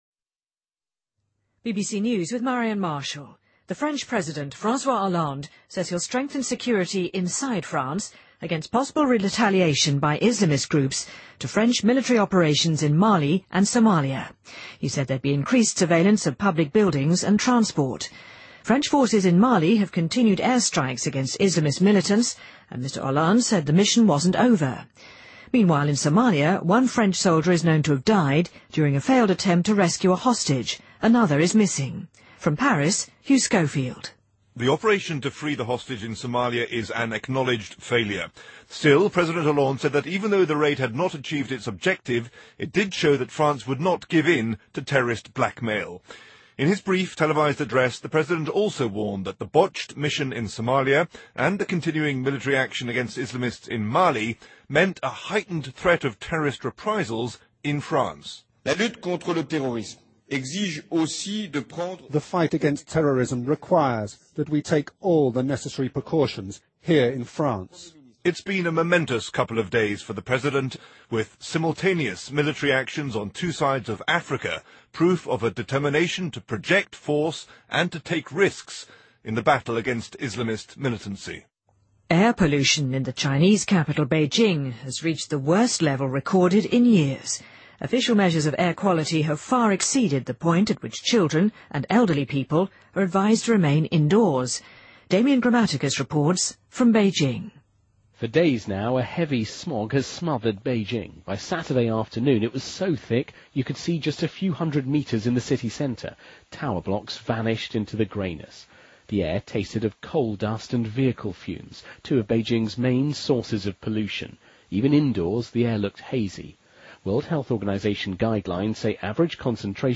BBC news,中国首都北京的空气污染达到数年来最高点